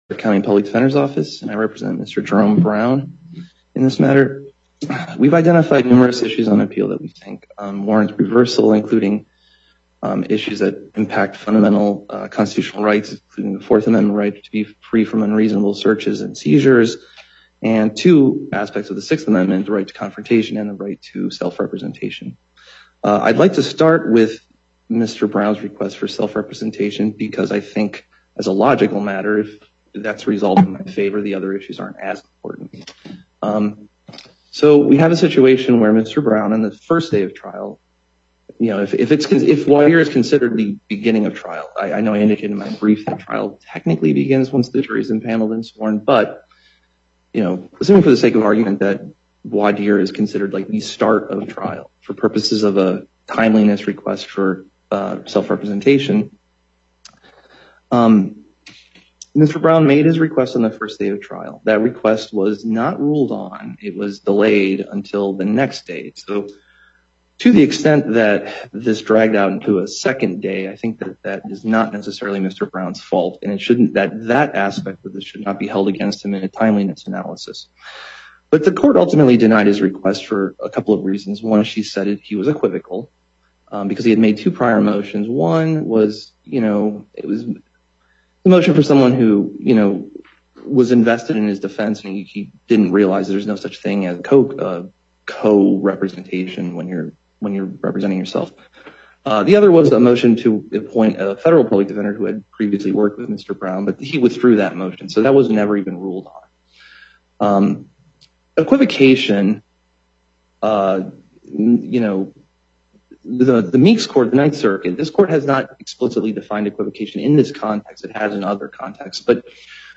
Loading the player Download Recording Docket Number(s): 85934 Date: 04/17/2024 Time: 11:30 AM Location: Las Vegas Justice Stiglich presiding, Before Panel B24.